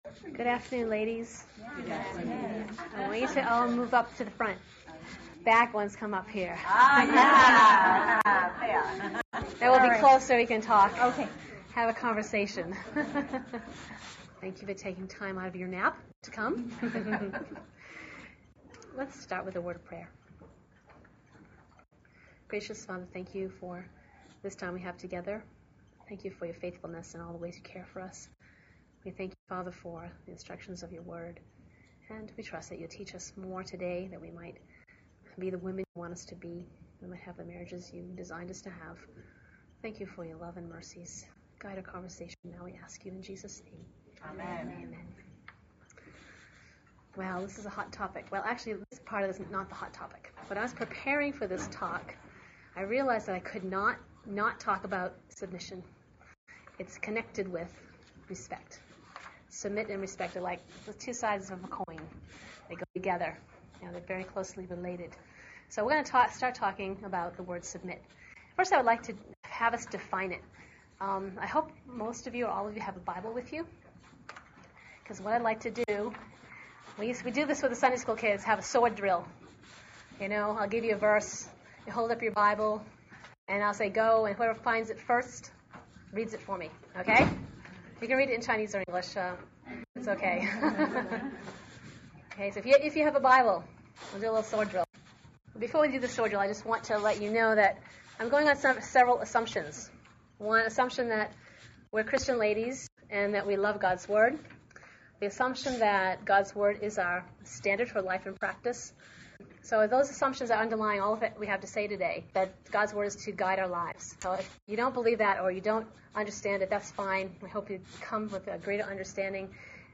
gave this talk to a group of sisters at a church retreat